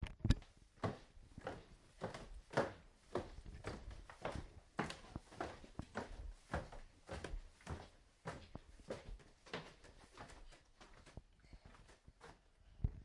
Stavba Construction site " Schody kovova konstrukce
描述：走在金属楼梯上
标签： 步骤 楼梯 金属
声道立体声